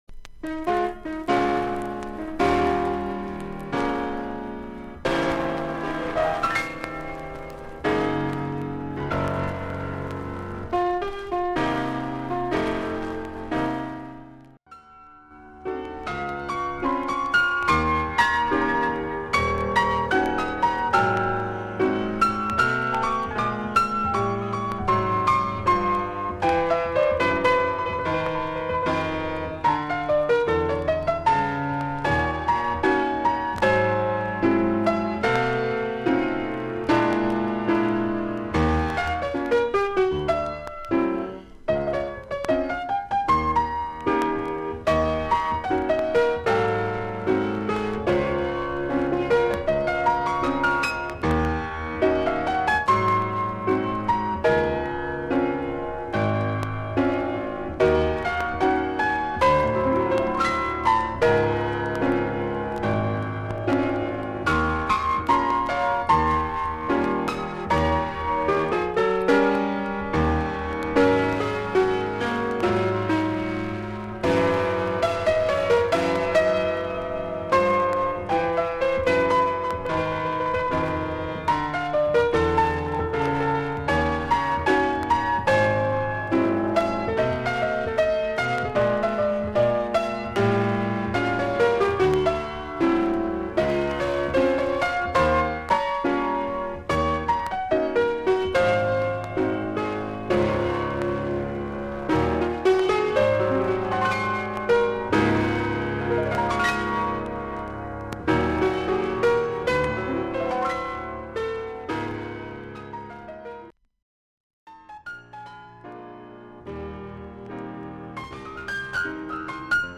少々サーフィス・ノイズあり。クリアな音です。
ジャズ・ピアニスト。ソロ・ピアノで奏でる古き良きスタンダード・ナンバー。